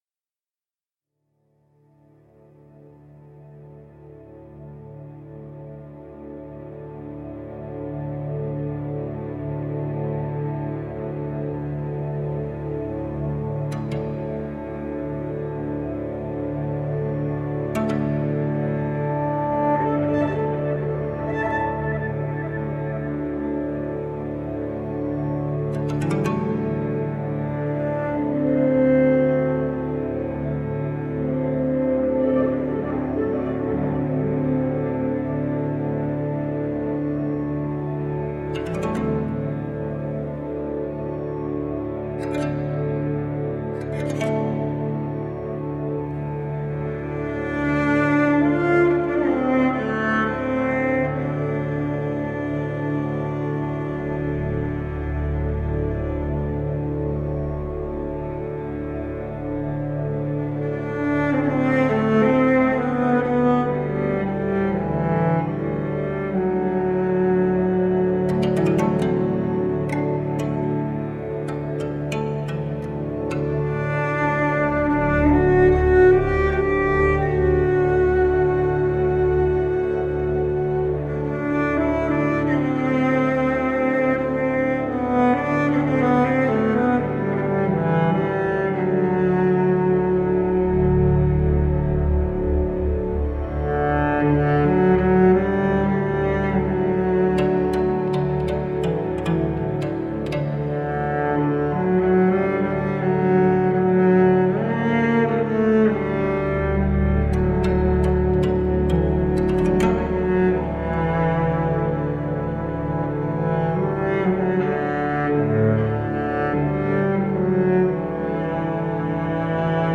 Dreamy music
Tagged as: New Age, Ambient, Cello, Ethereal